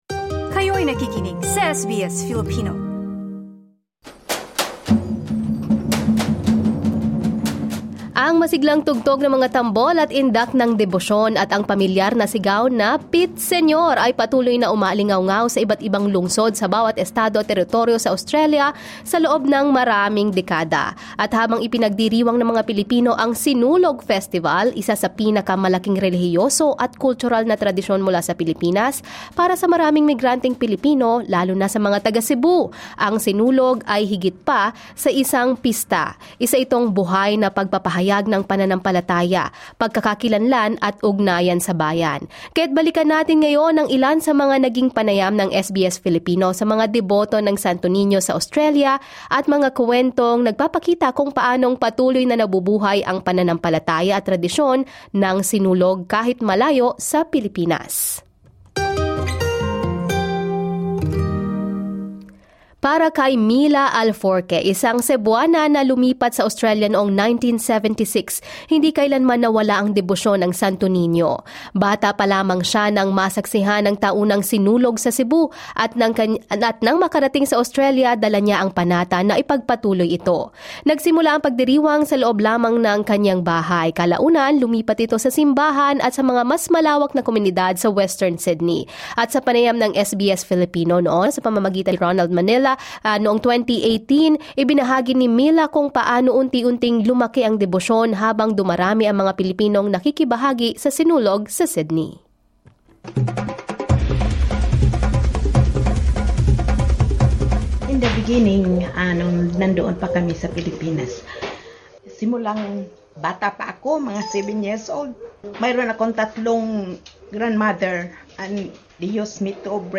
Balikan ang ilan sa mga naging panayam ng SBS Filipino sa mga deboto ng Santo Niño sa Australia, mga kuwentong nagpapakita kung paano patuloy na nabubuhay ang pananampalataya at tradisyon ng Sinulog, kahit malayo sa Pilipinas.